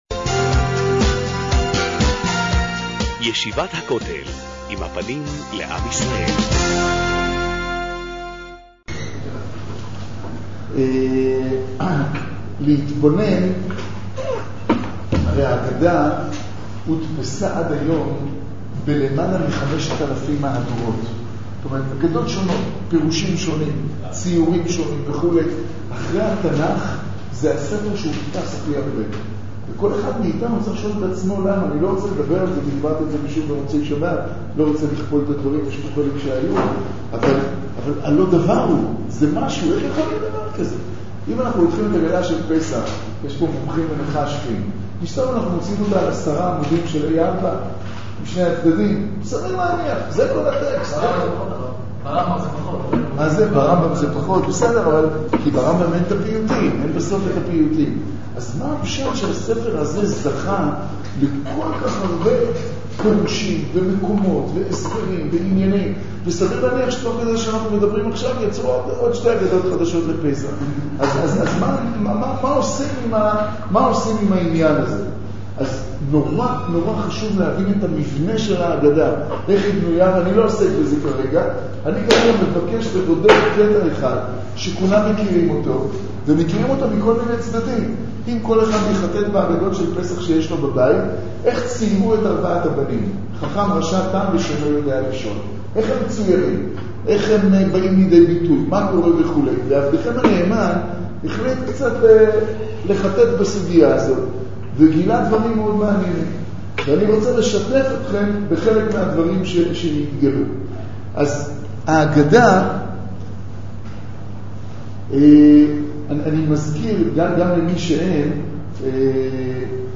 מאגר שיעורים תורני